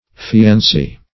Search Result for " fiancee" : Wordnet 3.0 NOUN (1) 1. a woman who is engaged to be married ; [syn: fiancee , bride-to-be ] The Collaborative International Dictionary of English v.0.48: Fianc'ee \Fi`an`c['e]e"\, n. [F.]